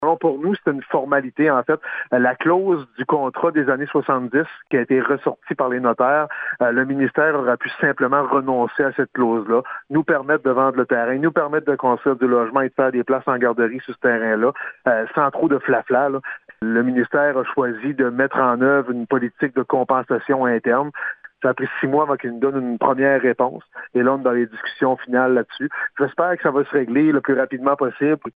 Le maire Daniel Côté explique que cette clause retarde en ce moment le processus d’acquisition du terrain chez le notaire :